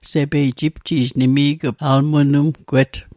Pronunciation Guide: mênêm·gu·et